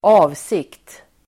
Uttal: [²'a:vsik:t]